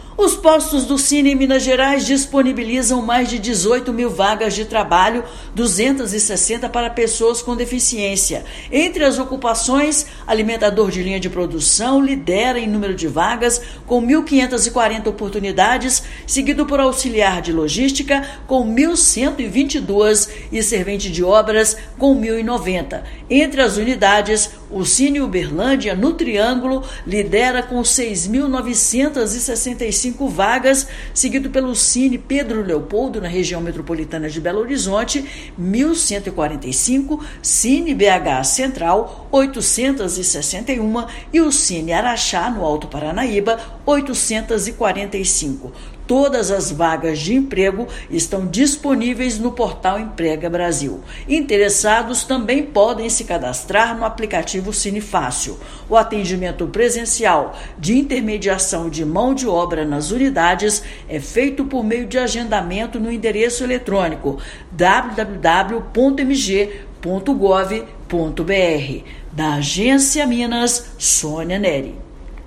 [RÁDIO] Postos do Sine em Minas Gerais têm mais de 18,3 mil vagas de trabalho
Alimentador de linha de produção é a ocupação com o maior número de oportunidades. Ouça matéria de rádio.